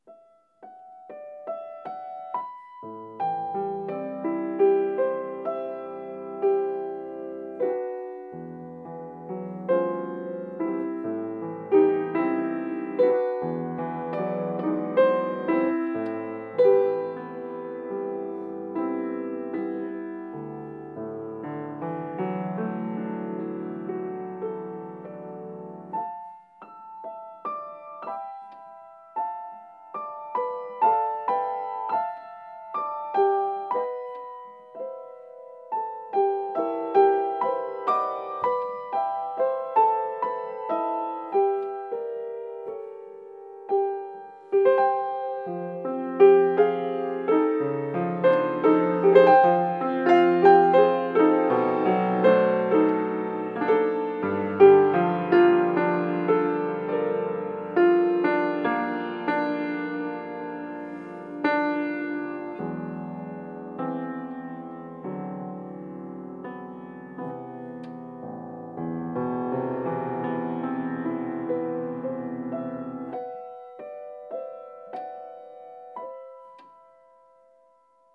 solo piano
Instrument: Piano
Style: Classical